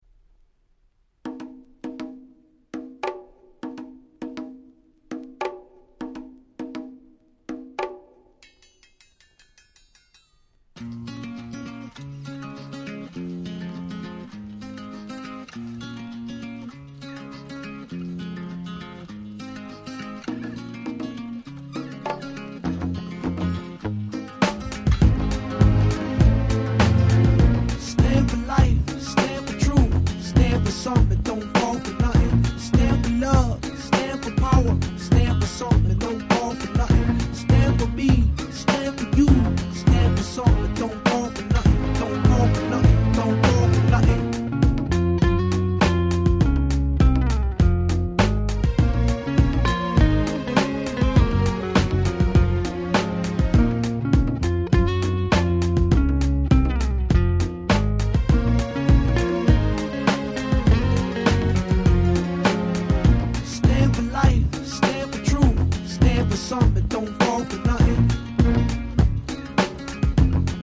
06' Nice Hip Hop !!